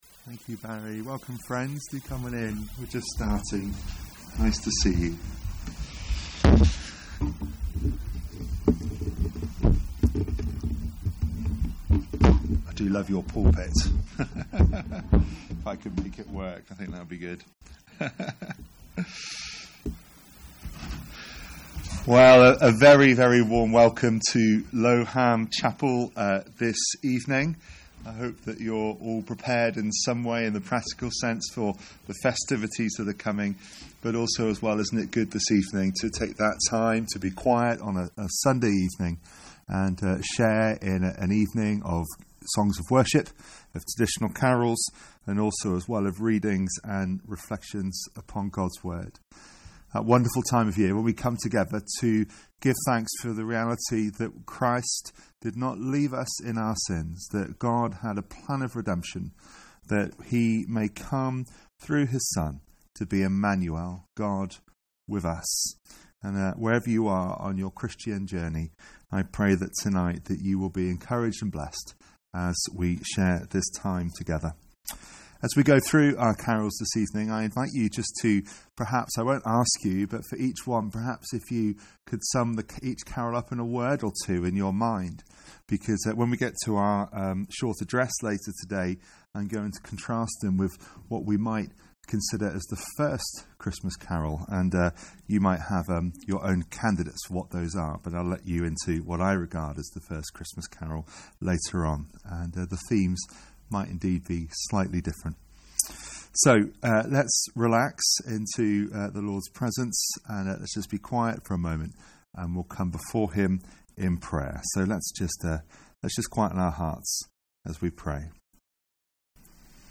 Christmas Carol Service